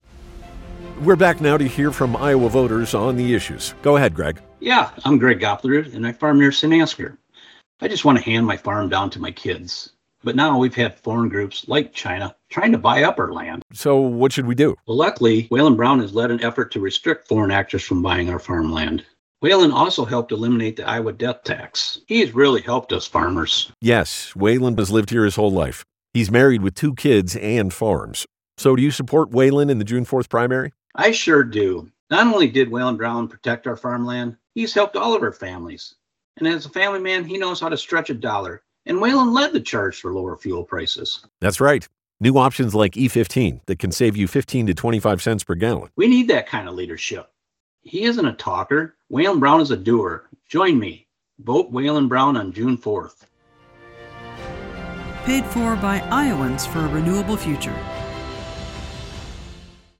The renewable fuels group bought radio ads promoting Brown on some Mason City stations as well; you can